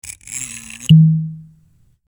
Cork Pop
Cork_pop.mp3